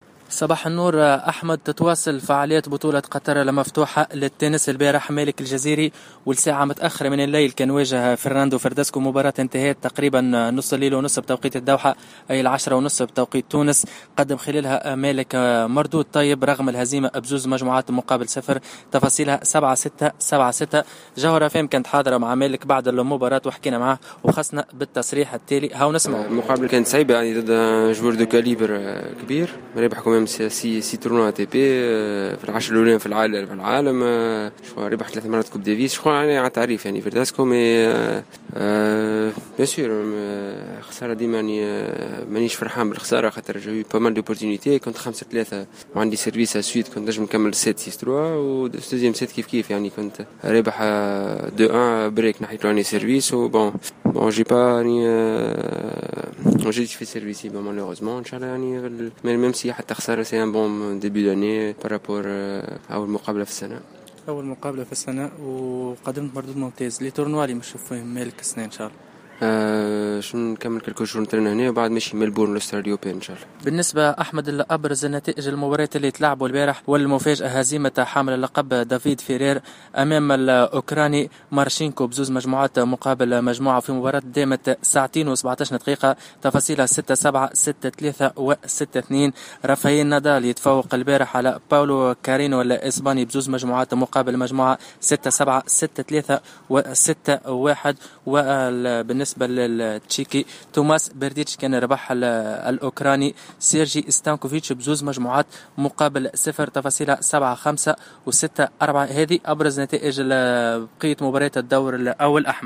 الحوار